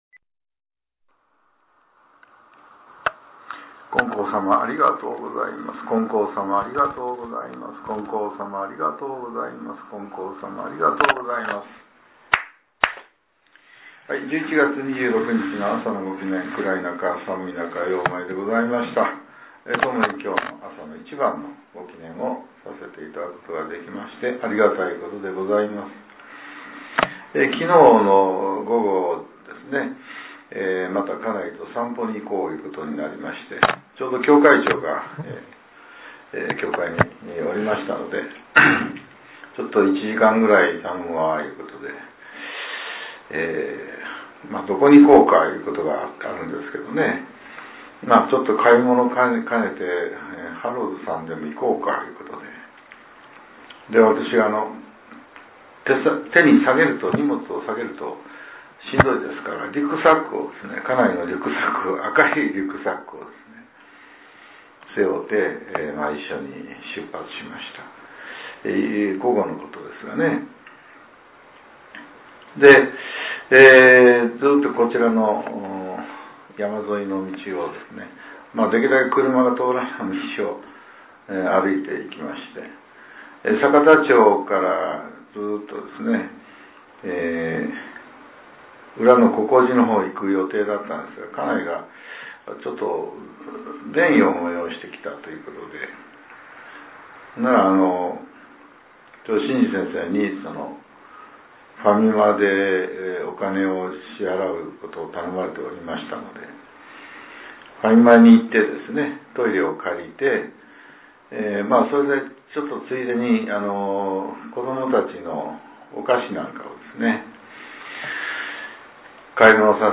令和７年１１月２６日（朝）のお話が、音声ブログとして更新させれています。